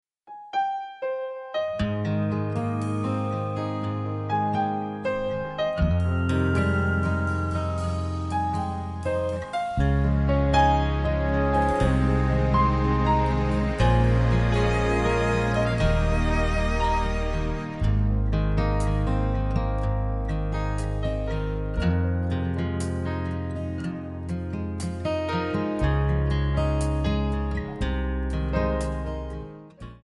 MPEG 1 Layer 3 (Stereo)
Backing track Karaoke
Country, 2000s